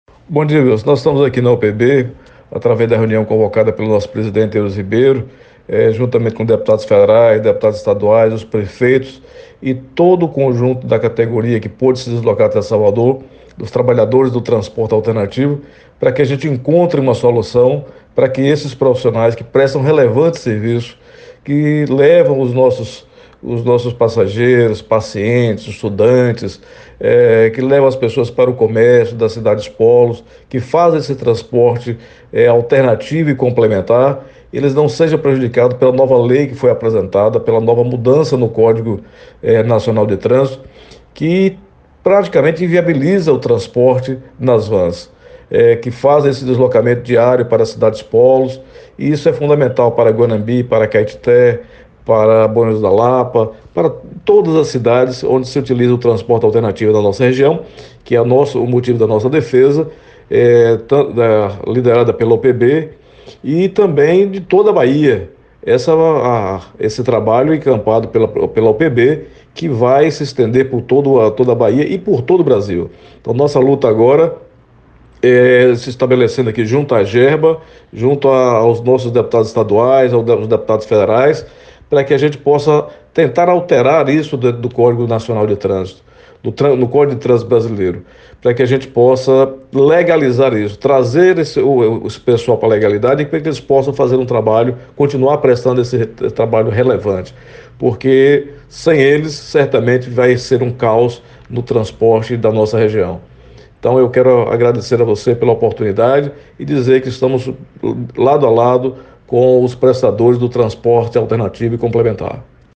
Prefeito de Riacho de Santana participa de encontro na UPB em busca de soluções para o transporte alternativo; ouça depoimento